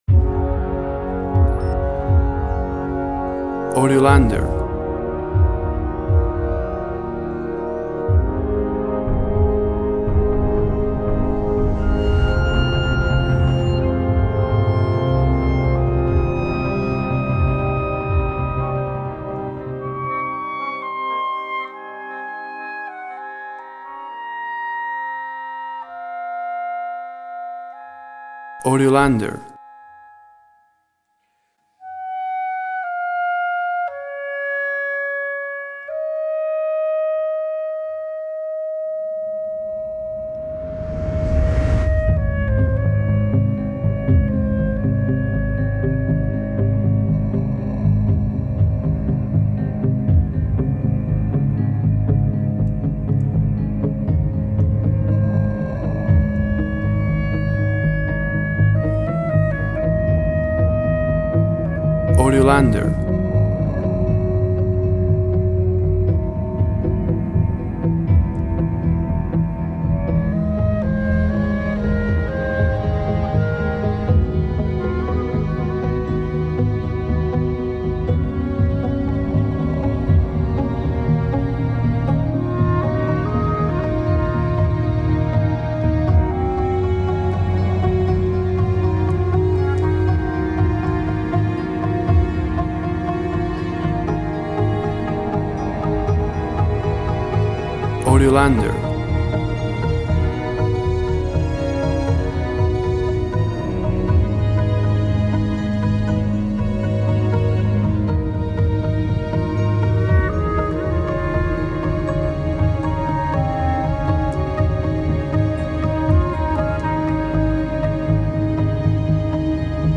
Cinematic sound for clarinet solo & hybrid orchestra.
Tempo (BPM) 120